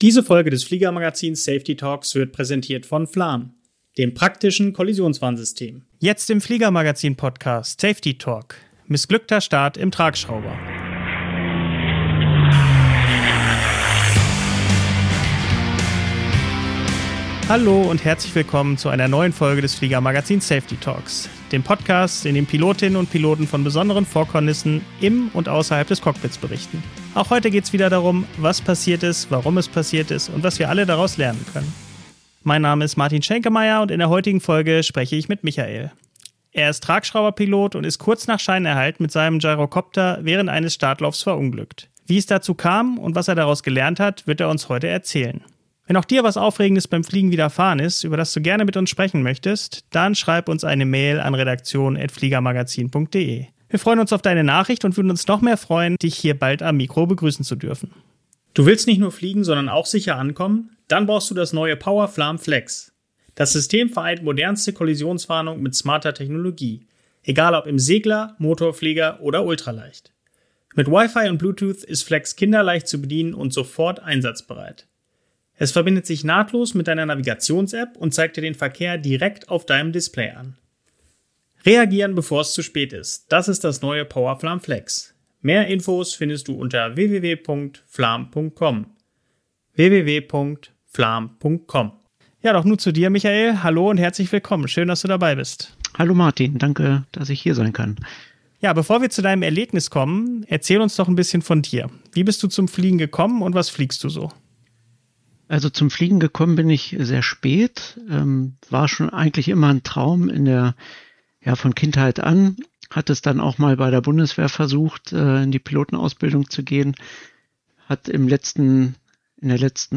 Wir reden offen über Fehlerkultur, Learnings aus dem Vorfall und darüber, wie wichtig es ist, Rückschläge nicht zu verschweigen, sondern daraus zu wachsen. Wir haben selten so offen und reflektiert mit einem Unfallpiloten über die Herausforderungen und Chancen der Fliegerei gesprochen.